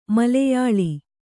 ♪ maleyāḷi